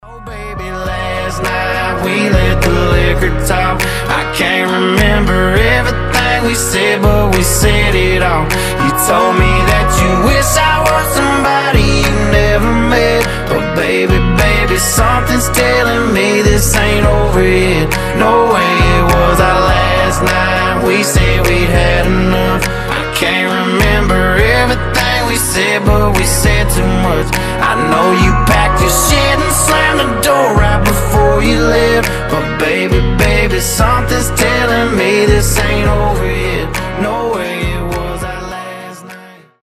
гитара , cover , кантри , мужской голос